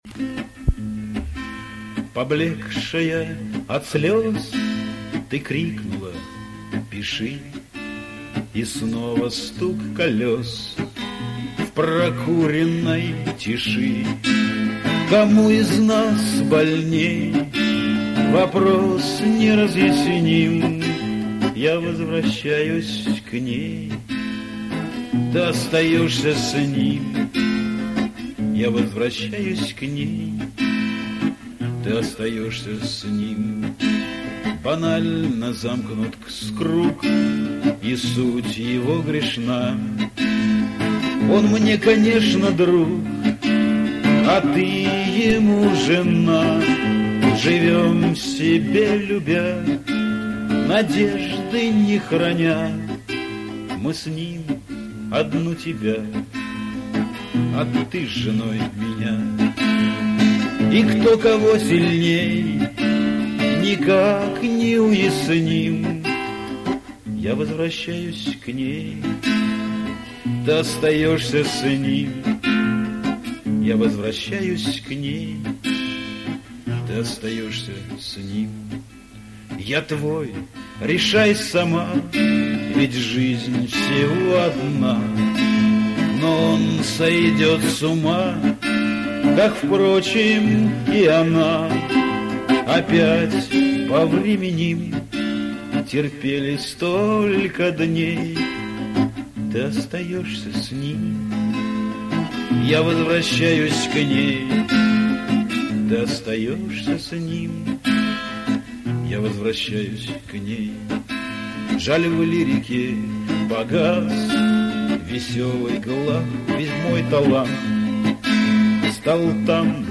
Шансон
Исполнение под гитару